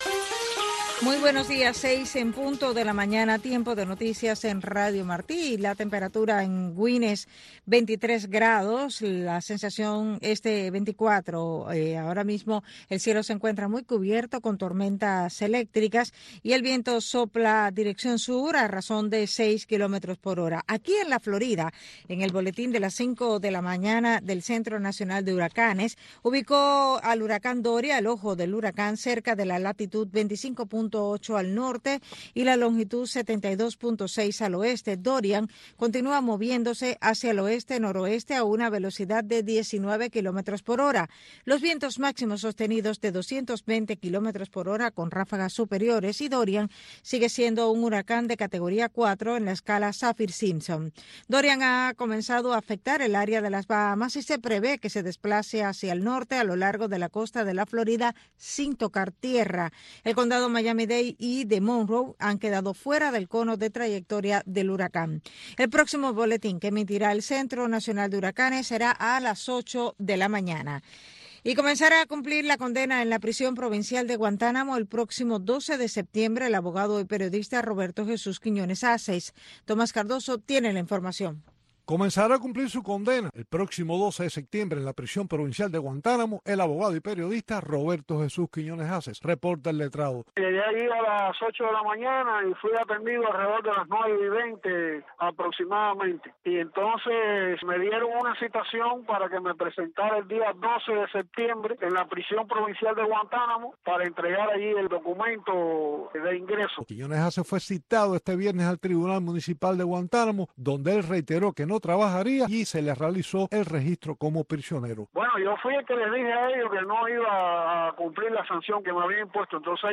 Radio Martí te presenta todos los sábados y domingos entre 6 y 8 de la mañana el bloque religioso “La Religión en Martí” en donde te presentaremos diferentes voces de académicos, pastores y hombres de fe que te traerán la historia y la palabra esperanzadora del señor.